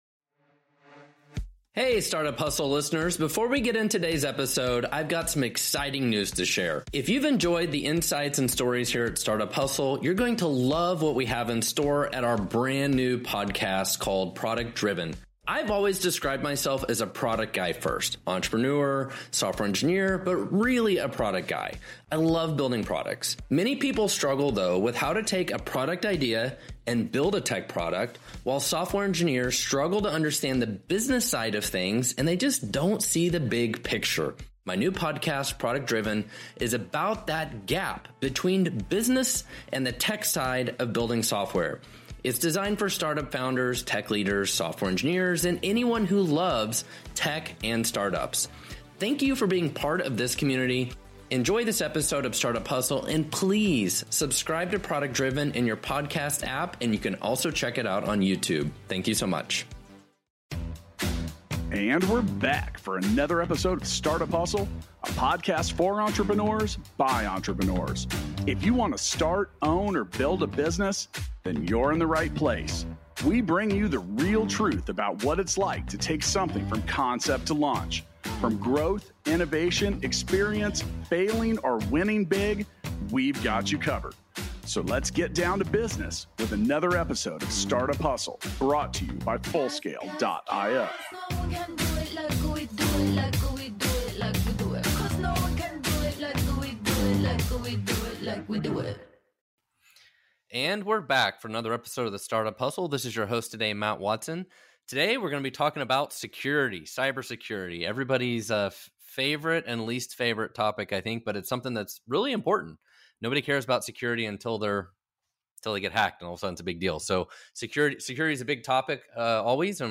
Hear their candid conversation about cybersecurity, the threats businesses face, and how to be better prepared. Learn why there is a need to augment cybersecurity practitioners’ skills and what the future of cybersecurity looks like with AI out front along with other new technologies.